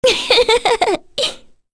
Kirze-Vox_Happy2_kr.wav